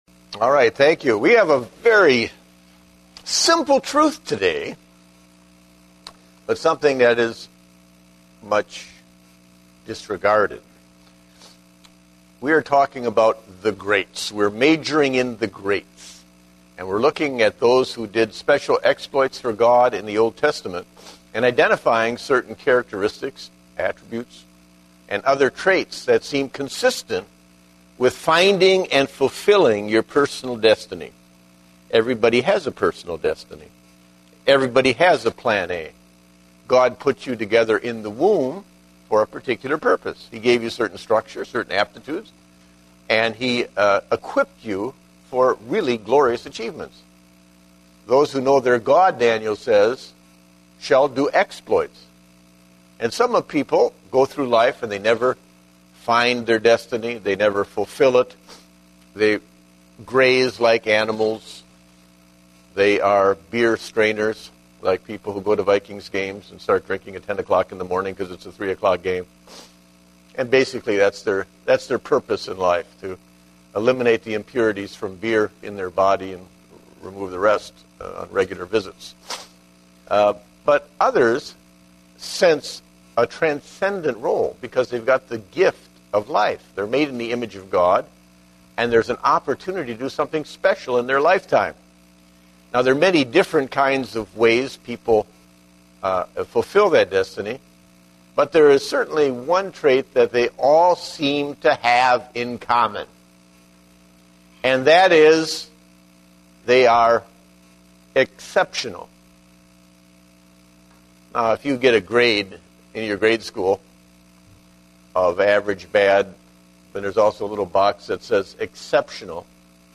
Date: October 31, 2010 (Adult Sunday School)